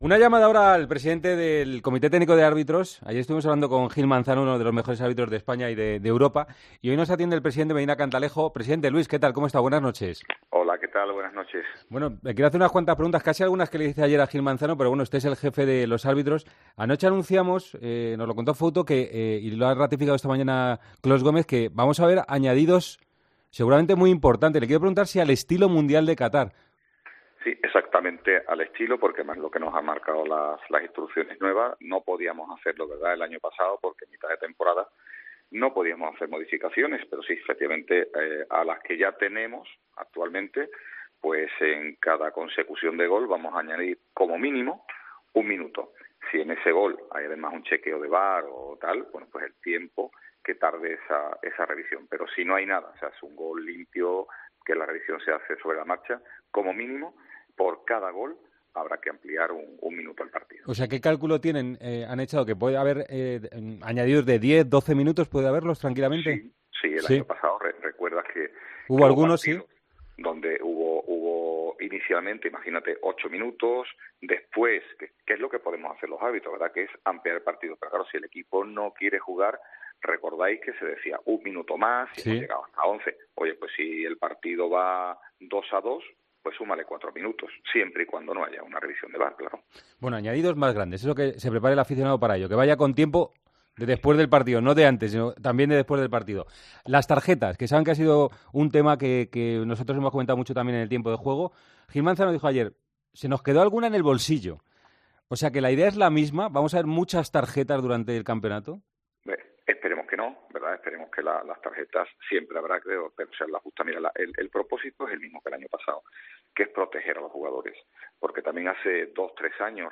El presidente del Comité Técnico de Árbitros habló este jueves de los cambios en el reglamento para la nueva temporada y los confirmó en El Partidazo de COPE.